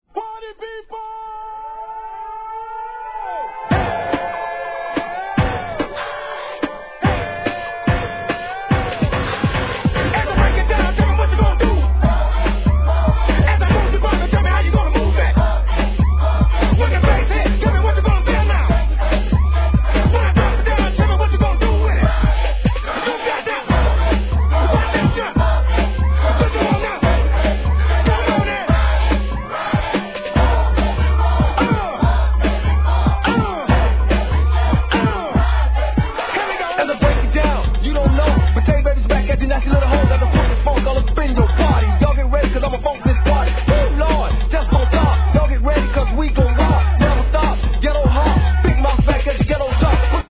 G-RAP/WEST COAST/SOUTH
1994年、高速HIGHテンションなマイアミ・ベース!!